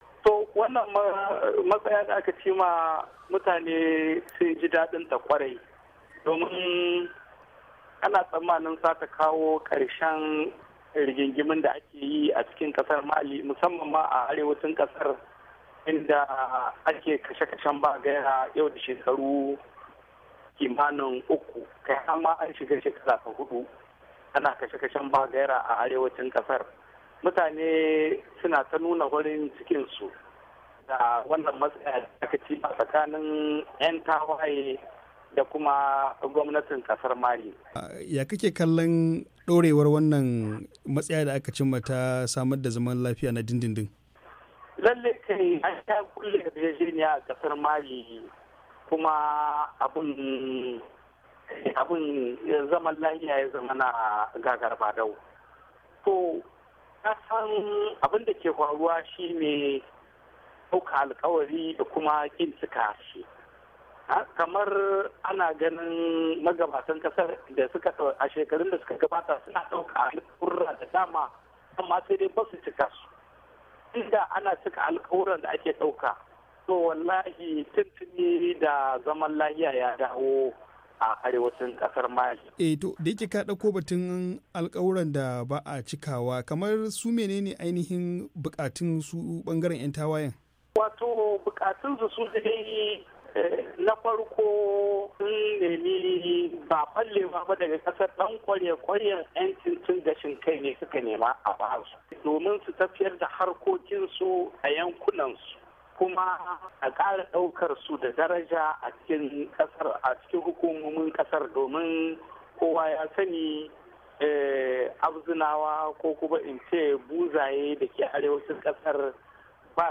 Muryar Amurka ta zanta da wani masani kan harkokin kasar Mali dake zaune a kasar kan yadda mutane suka karbi labarin cimma daidaito da 'yan tawayen.